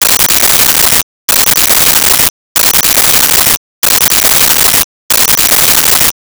Scanner
Scanner.wav